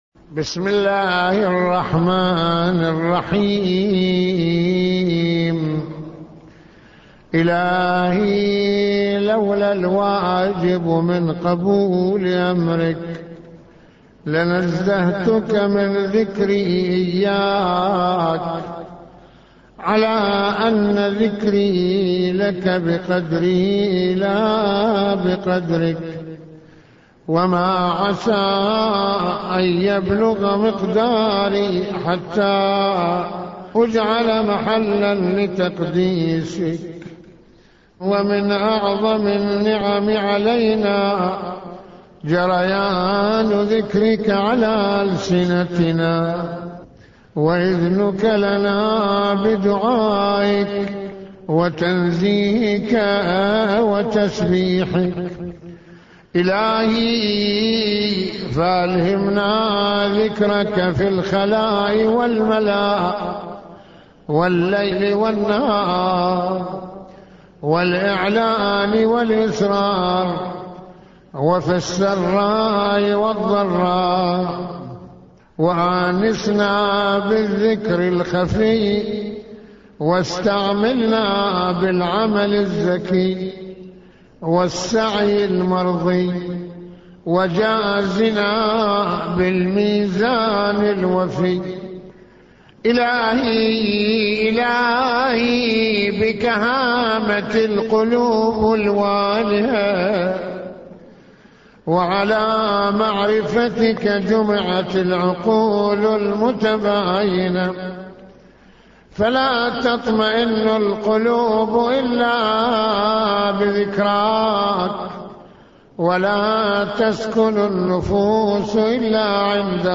- استمع للدعاء بصوت سماحته